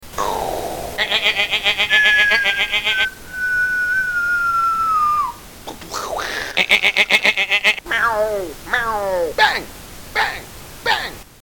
Battle sound effects